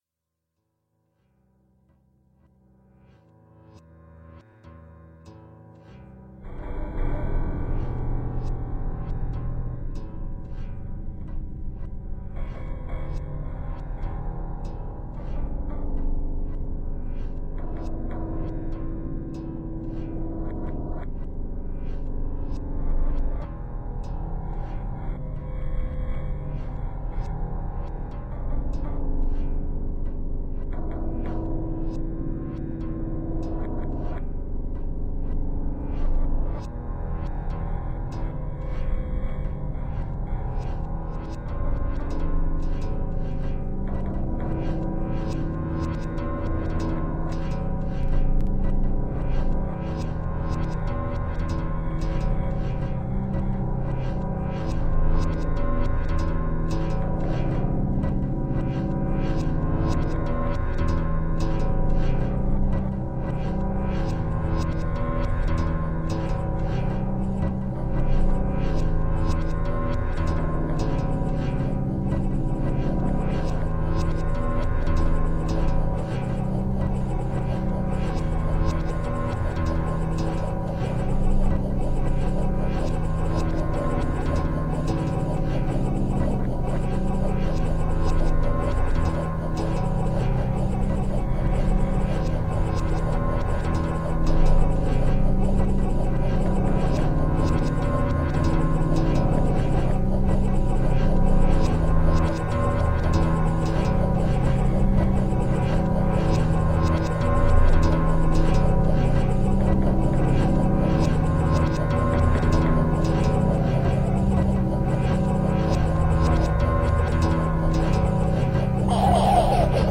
I wanted to do something atmospheric.
With that general concept in mind, I took two sound files that I had recorded with my iPhone as a sort of test to see how well it would work with field recordings and used them.
All of these parameters were manipulated in real time.
The streams were positioned on a stereo plane and over the course of seven minutes their positions gradually shifted.
10 days, creativity, original music